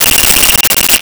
Cell Phone Ring 14
Cell Phone Ring 14.wav